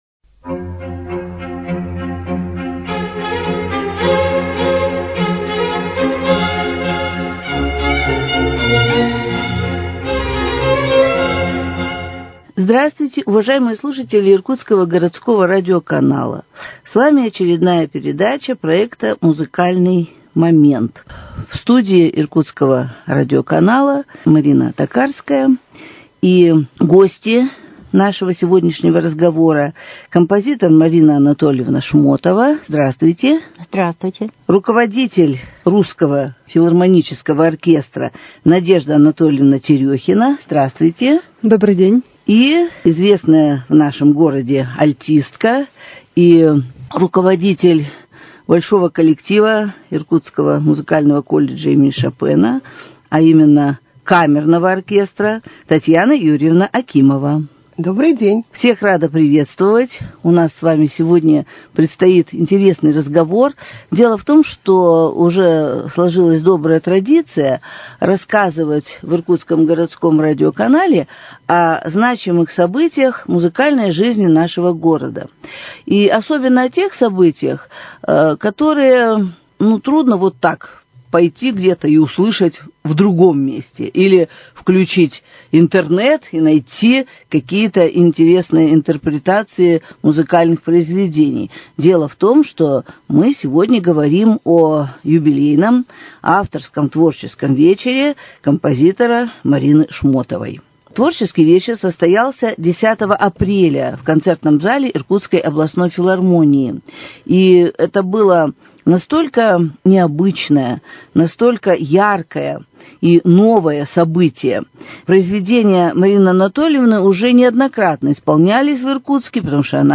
Беседу об этом событии ведут участники концерта